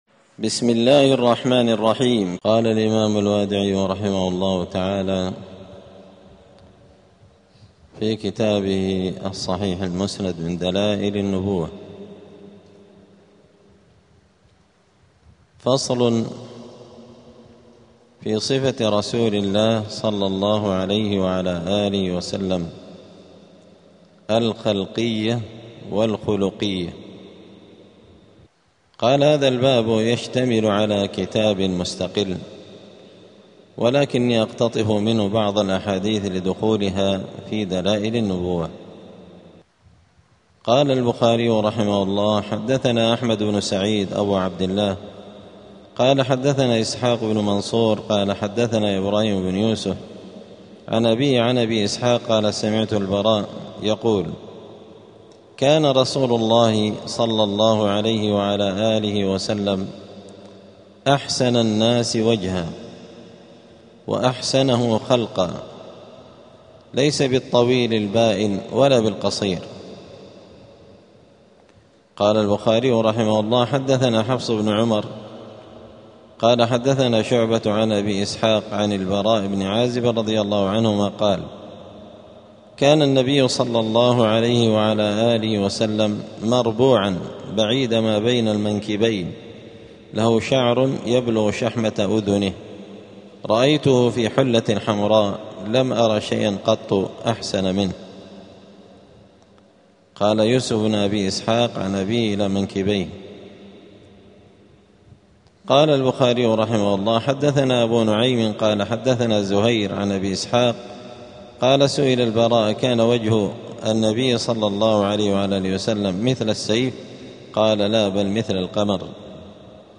*الدرس العاشر (10) {فصل في صفة رسول صلى الله عليه وسلم الخَلْقِية والخُلُقِية}.*
دار الحديث السلفية بمسجد الفرقان قشن المهرة اليمن 📌الدروس الأسبوعية